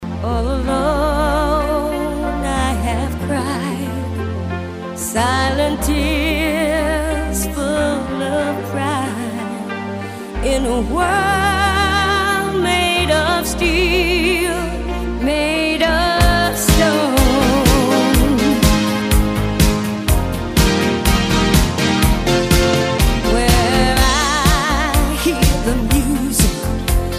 这是英文原唱